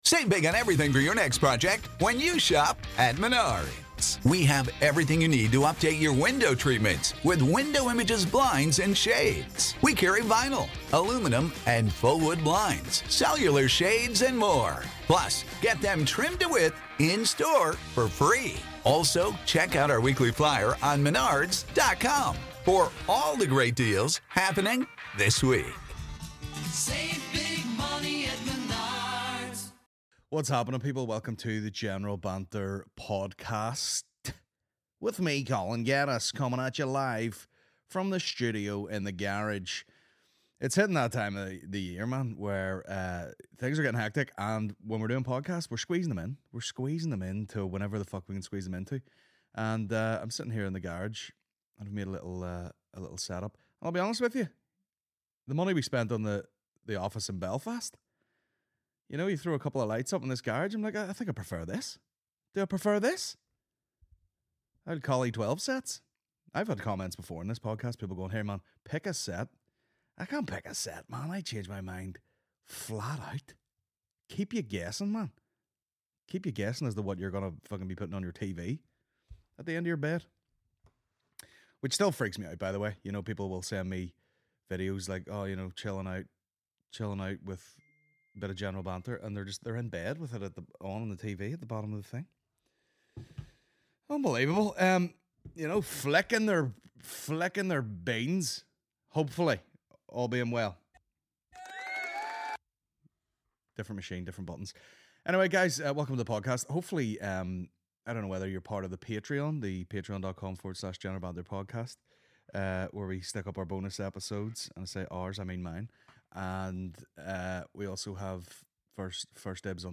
ELF & SAFETY General Banter Podcast General Banter Podcast Comedy 4.8 • 1.1K Ratings 🗓 19 December 2023 ⏱ 55 minutes 🔗 Recording | iTunes | RSS 🧾 Download transcript Summary On this week's podcast - accosted by elves, live gameshows, Bin Laden, Danger naps.